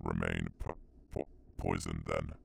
Voice Lines / Marcel drug fiend